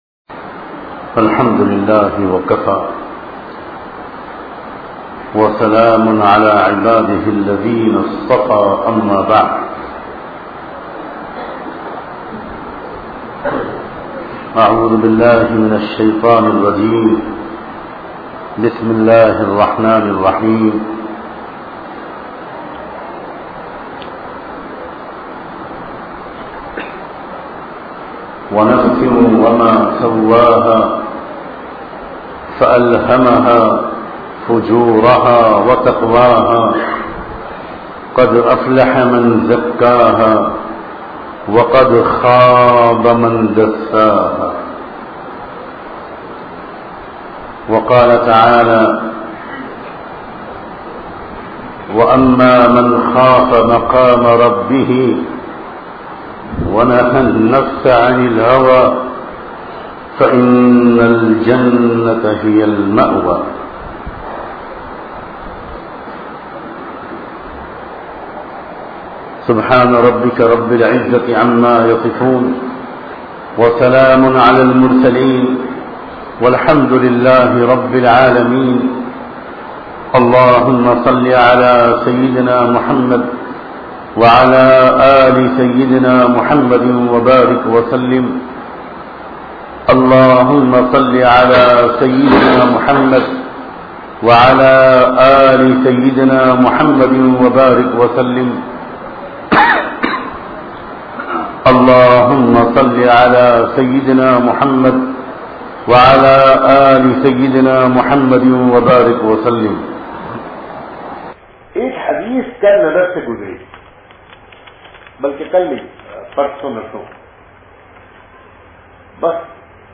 Apne Dil Ko Tatolte Rahiye bayan MP3